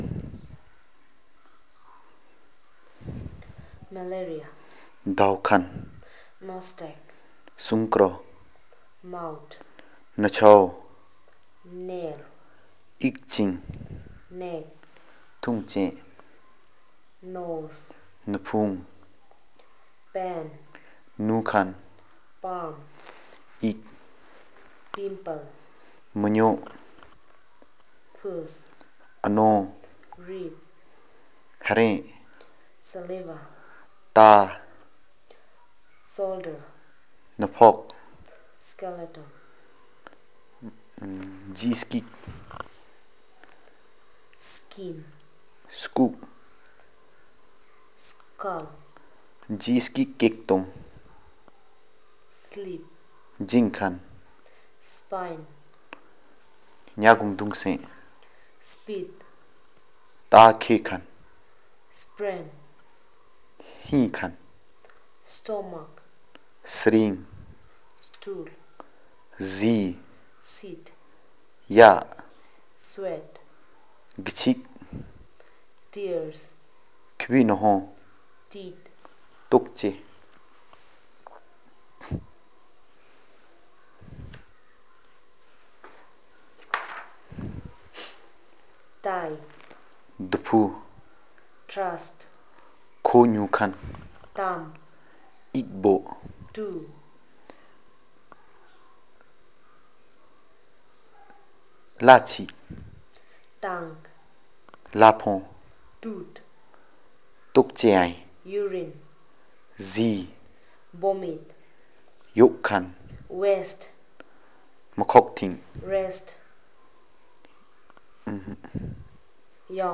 Elicitation of words about human body parts, diseases and body excretions and secretions